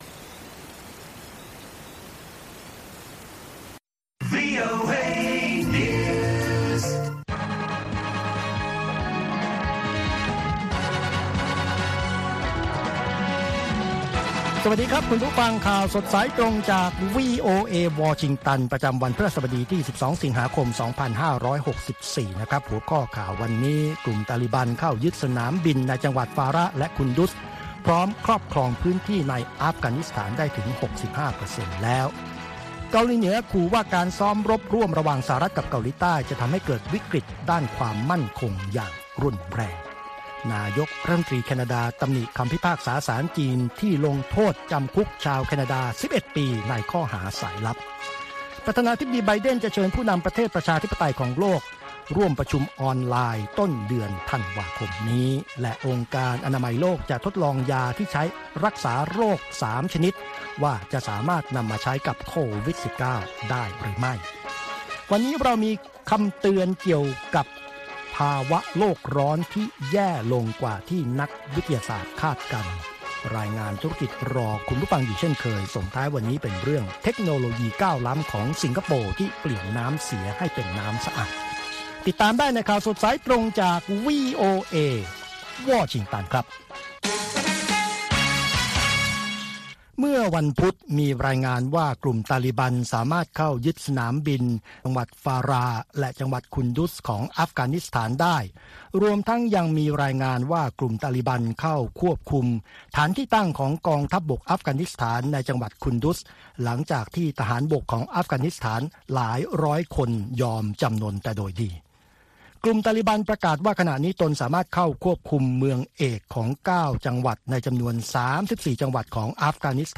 ข่าวสดสายตรงจากวีโอเอ ภาคภาษาไทย 6:30 – 7:00 น. ประจำวันพฤหัสบดีที่ 12 สิงหาคม 2564 น.